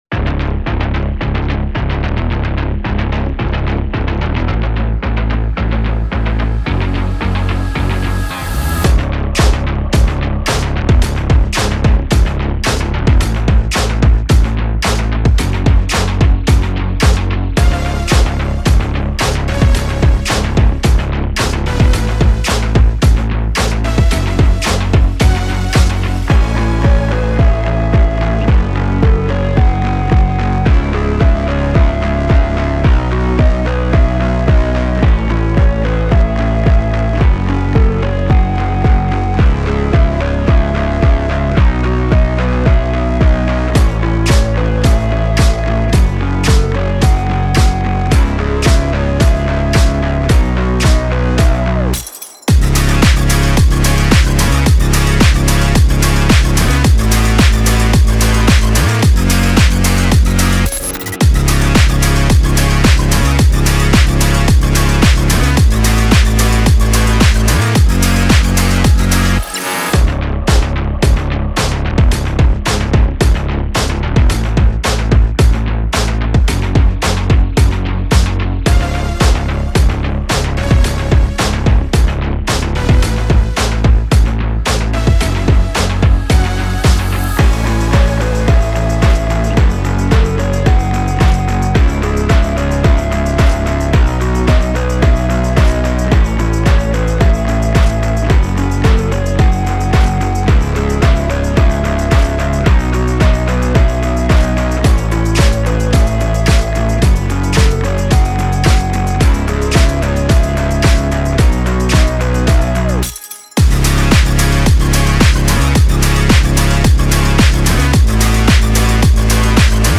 official instrumental
Pop Instrumentals